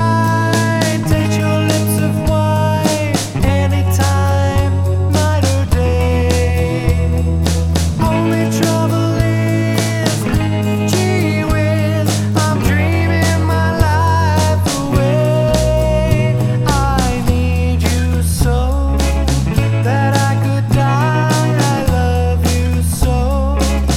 No Harmony Pop (1950s) 2:21 Buy £1.50